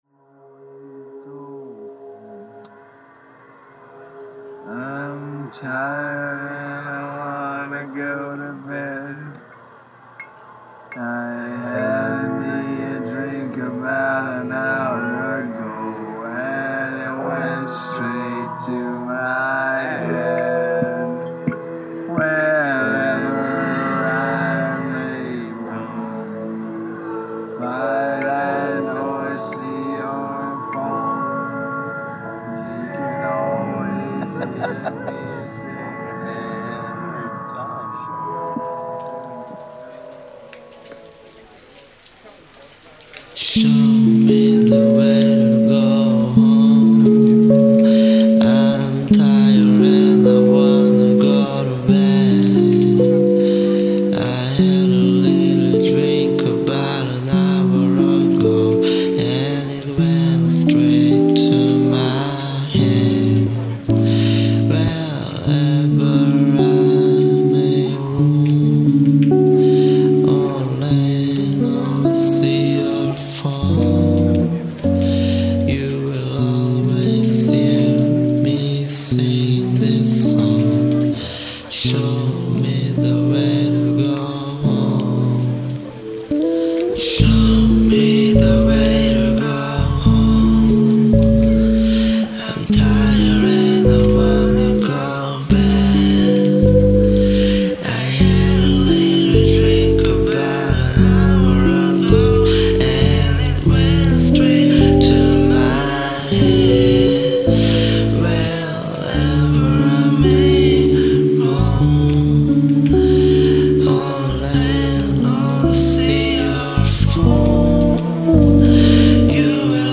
elec guitar & bass
synthesizer